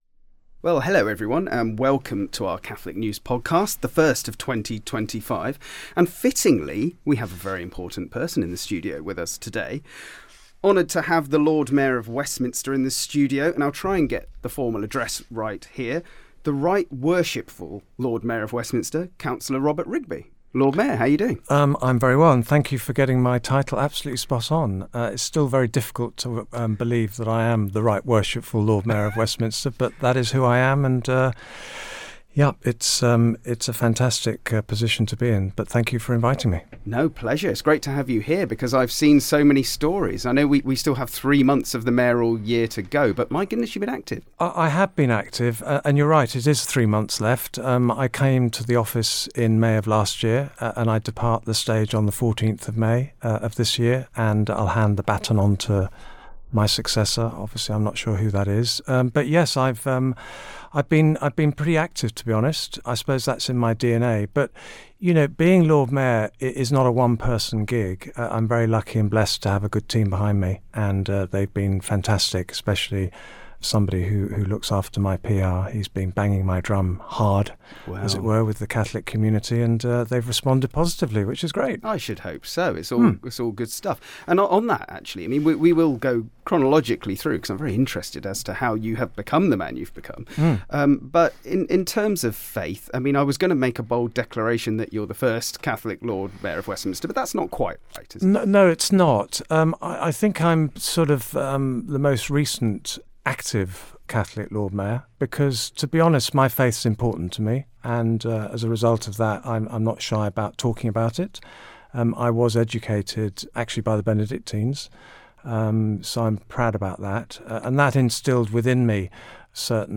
A special Catholic News podcast in which we speak to the charismatic Right Worshipful Lord Mayor of Westminster, Councillor Robert Rigby.